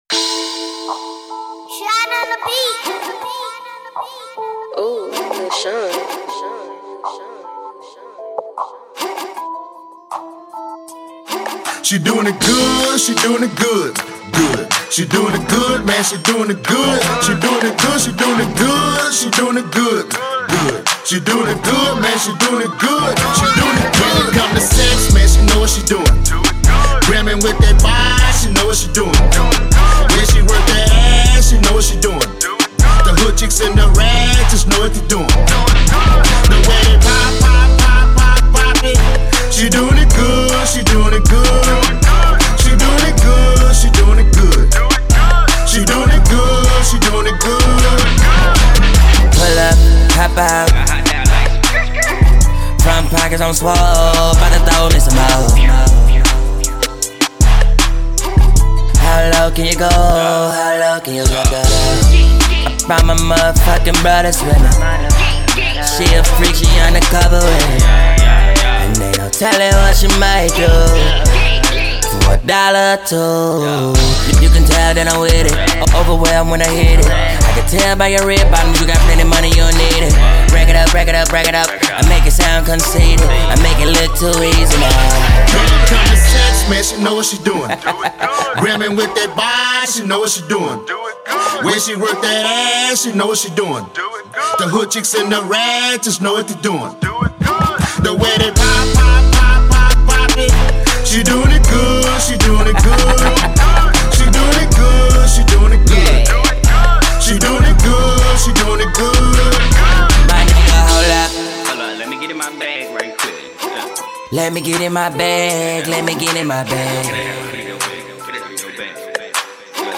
Hiphop
strip club banger